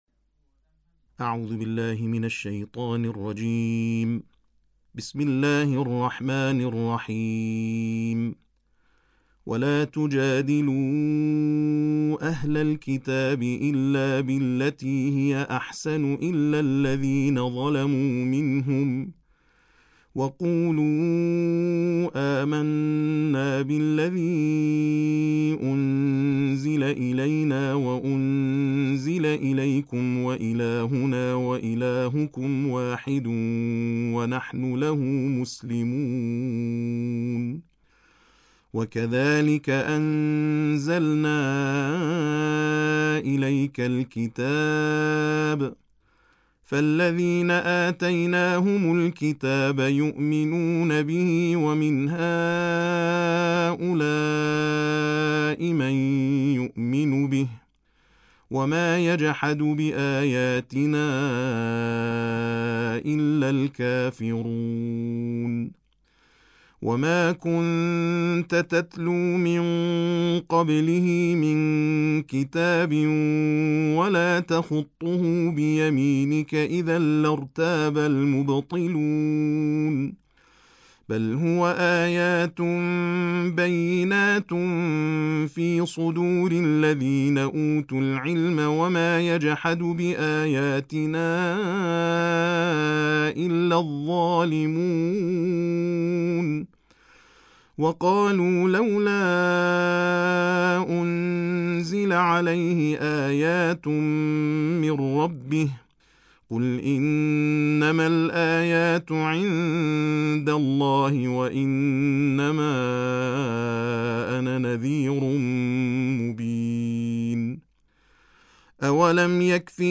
تلاوت جز 21 قرآن